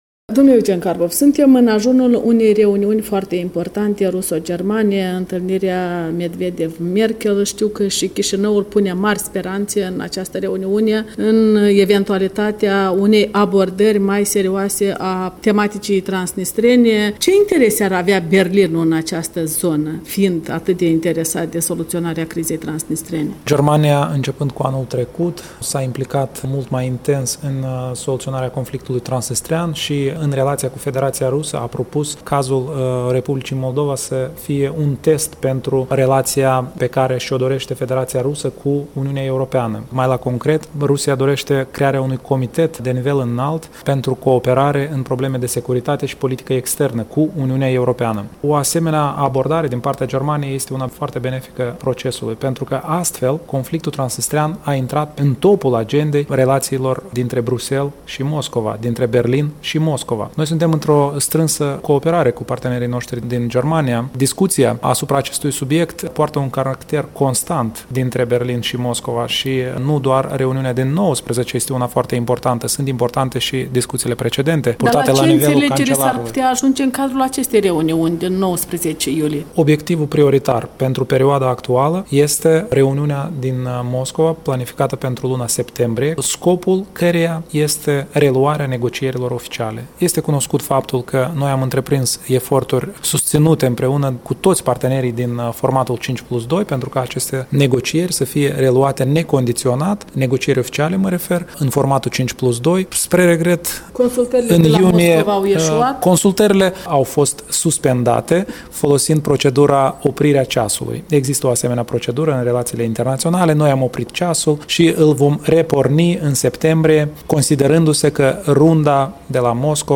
Vicepremierul pentru reintegrare Eugen Carpov răspunde întrebărilor